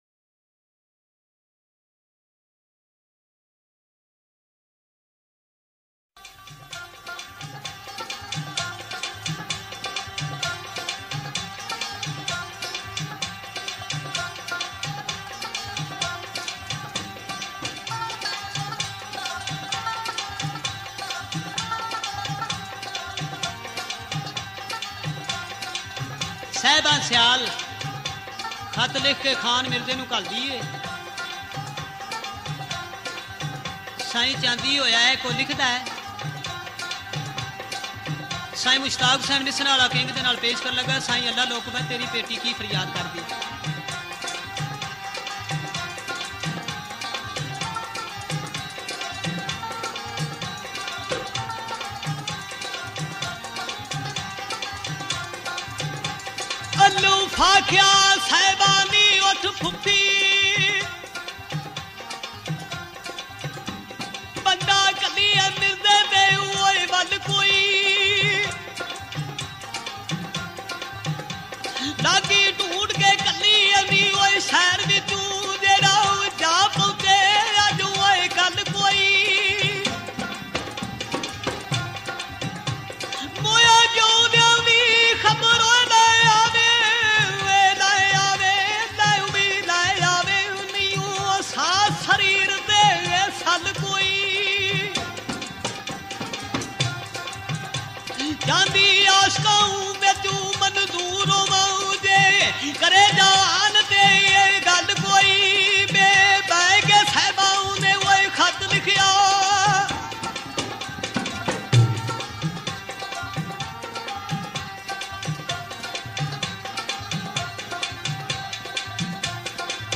Punjabi Folk